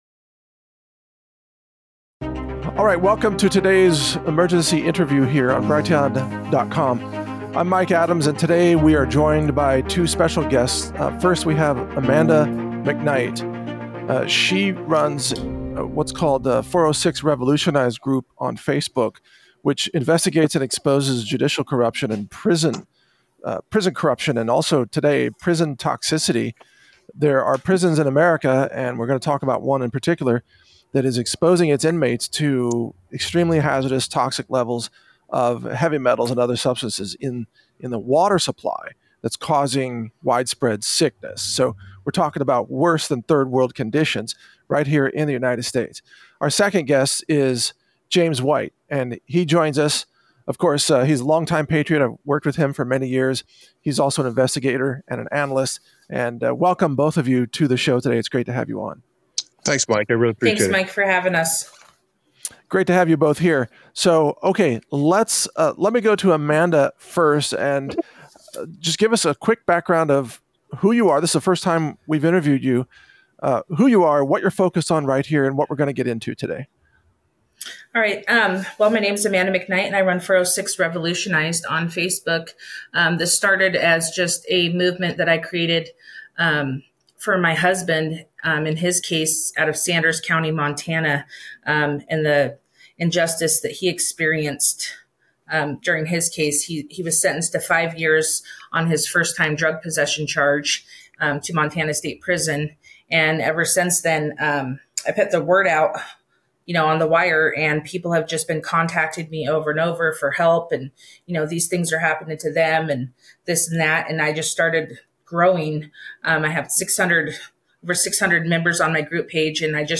-Introduction of Guests and Purpose of the Interview (0:02)